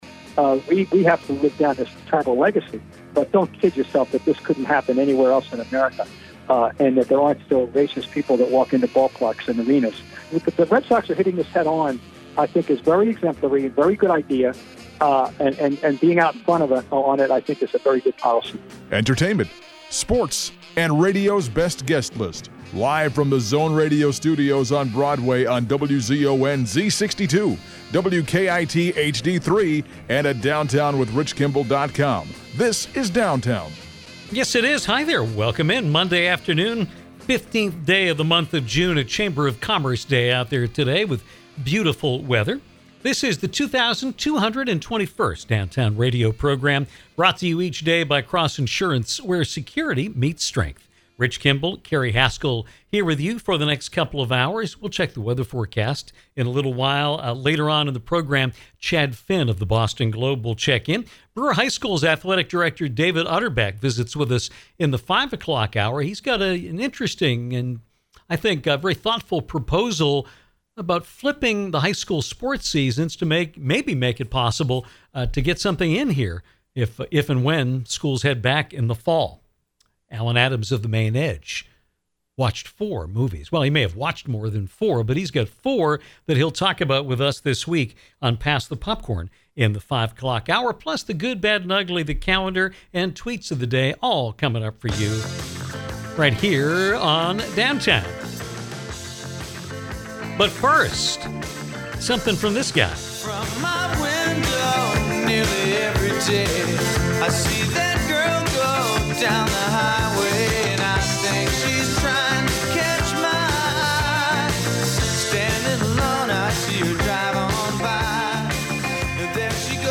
Singer-songwriter Marshall Crenshaw joined us to talk about his career, from Beatlemania to his Golden Globe-nominated work on “Walk Hard”, and we discussed a documentary project that’s in the works on legendary producer Tom Wilson. Marshall has re-issued his 1999 album “Miracle Of Science”, which also includes two new bonus tracks.